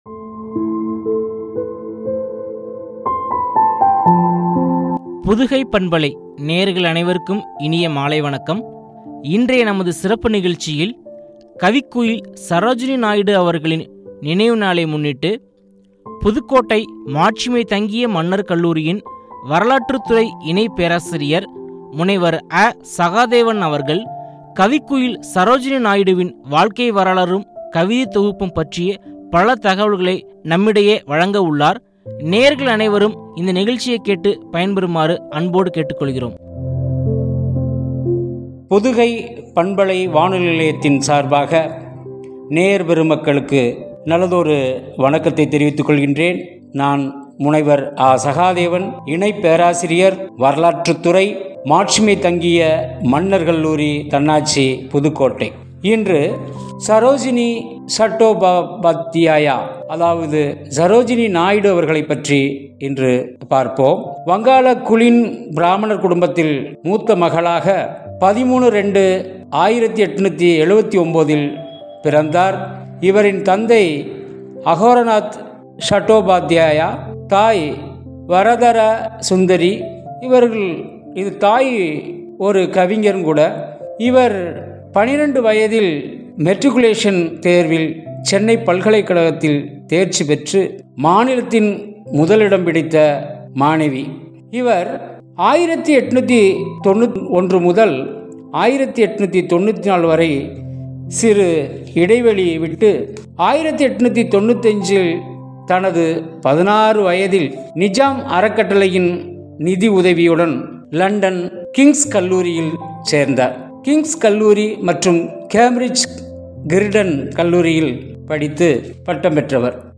கவிதை தொகுப்பும்” பற்றிய உரையாடல்.